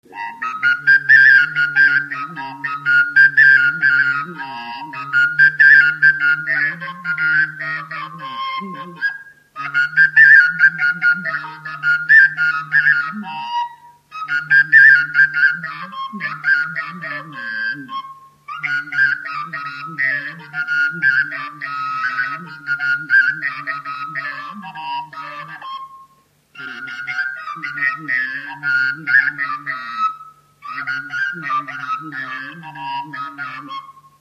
Dallampélda: Hangszeres felvétel
Moldva és Bukovina - Moldva - Magyarós (Forrófalva)
furulya
Stílus: 7. Régies kisambitusú dallamok